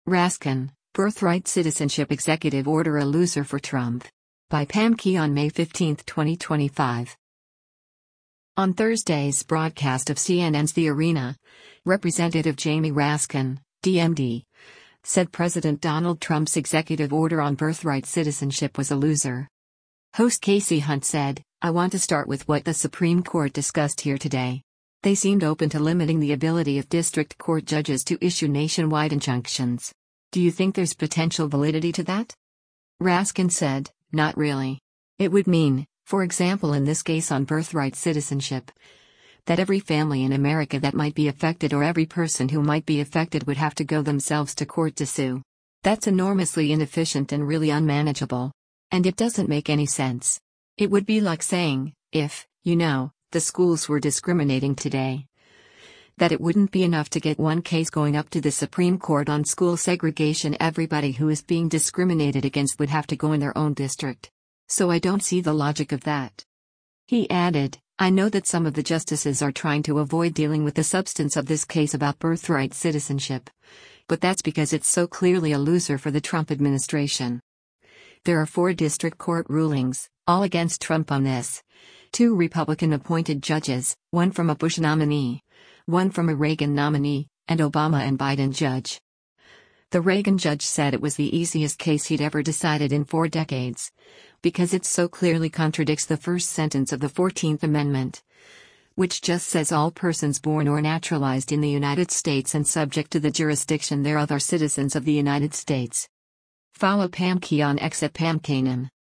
On Thursday’s broadcast of CNN’s “The Arena,” Rep. Jamie Raskin (D-MD) said President Donald Trump’s executive order on birthright citizenship was “a loser.”